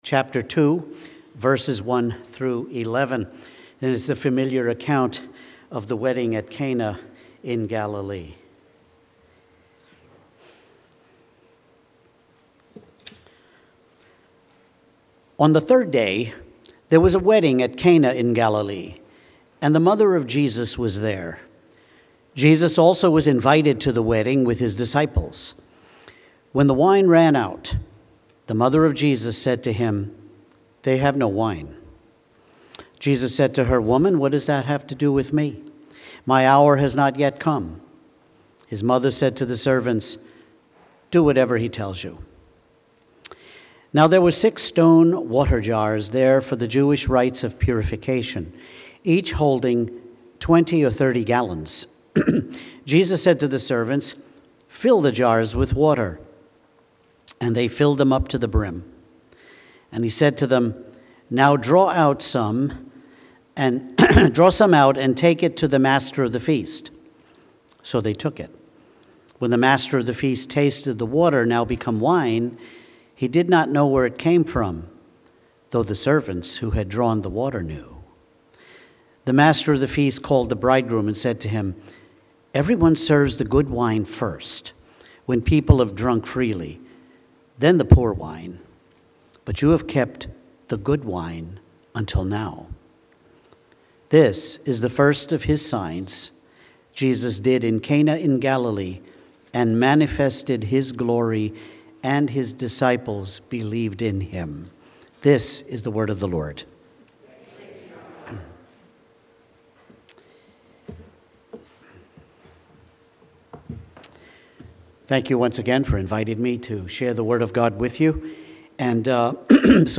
Sermons by the Pastors of Astoria Community Church